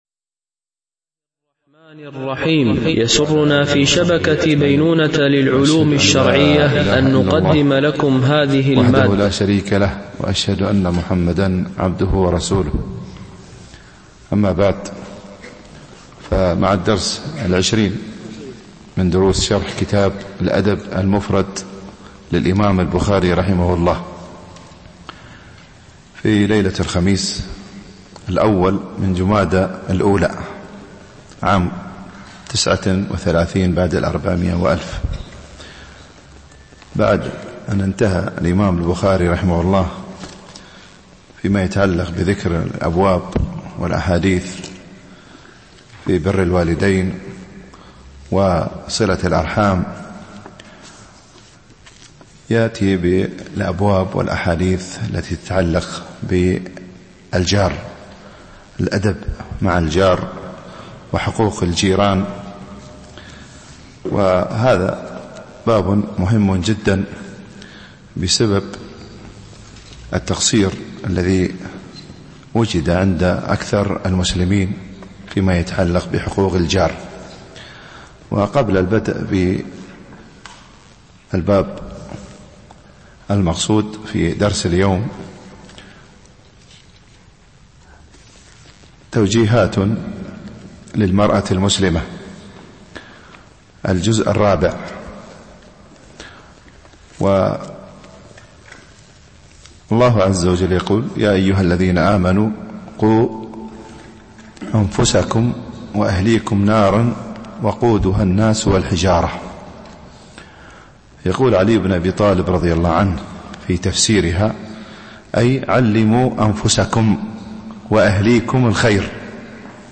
شرح الأدب المفرد للبخاري ـ الدرس 20 ( الحديث 101 - 106 )